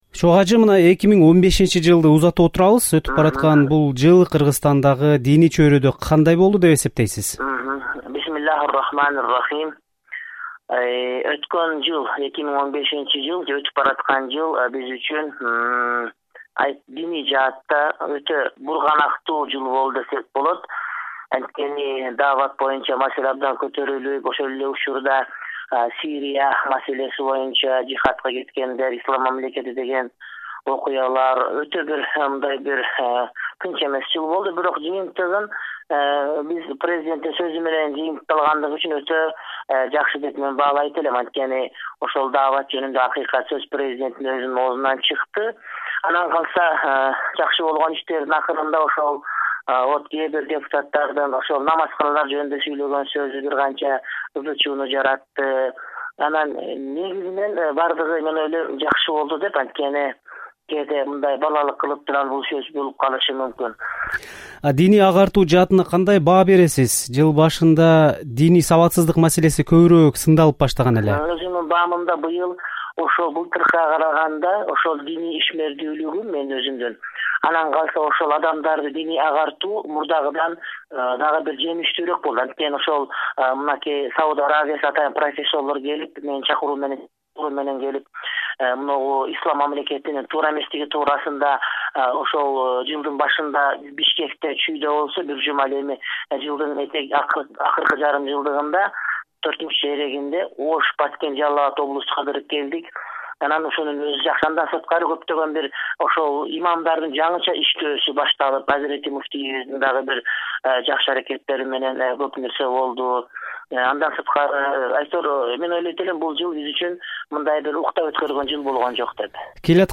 2015-жылы Кыргызстанда диний чөйрөдө кандай окуялар, бурулуштар болду? Ушул жана башка суроолордун тегерегинде Кыргызстандын мурдагы муфтийи, аалым Чубак ажы Жалилов “Азаттык” радиосуна маек куруп берди.